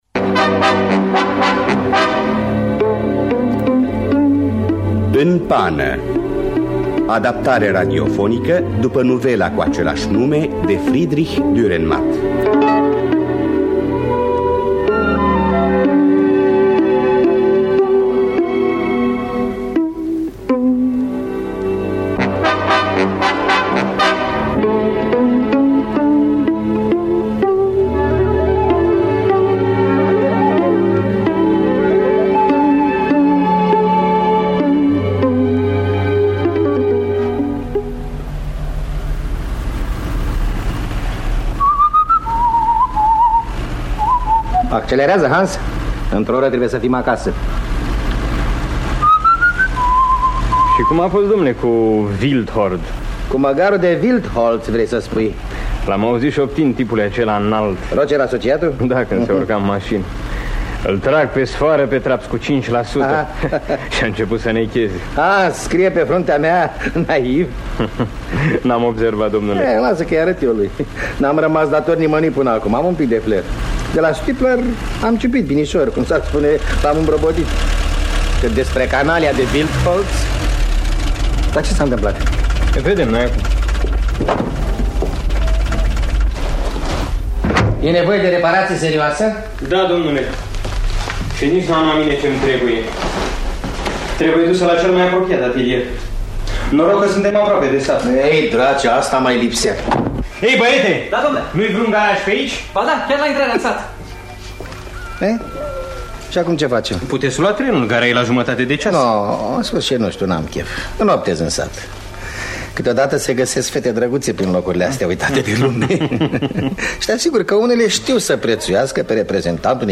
Adaptarea radiofonică
Înregistrare din anul 1963.